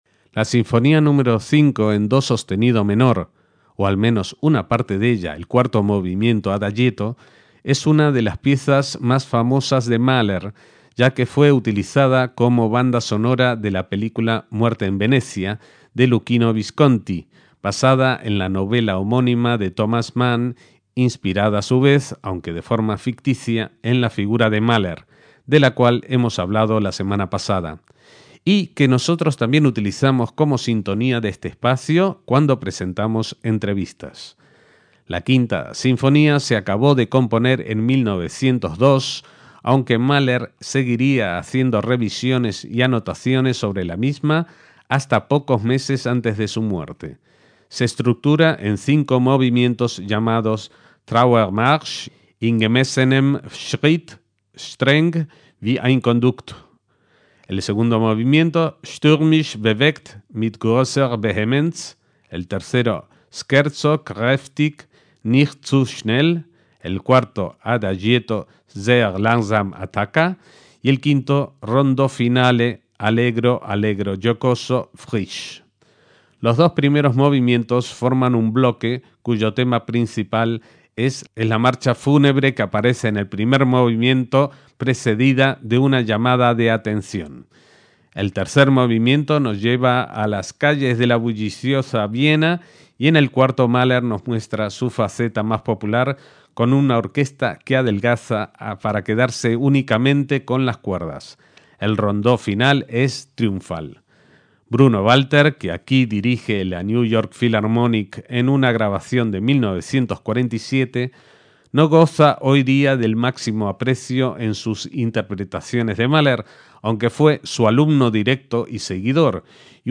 MAHLER: EL COMPOSITOR NACIONALISTA DE LA ASIMILACIÓN - Mahler acabó la composición de su Sinfonía nº 5 en do sostenido menor en 1902, aunque la revisó continuamente hasta el año anterior a su muerte.
El tema principal se centra en la marcha fúnebre precedida de una gran llamada de atención. El Adagietto de esta sinfonía es una excepción en la obra sinfónica del compositor pues es sólo para orquesta de cuerda y arpa.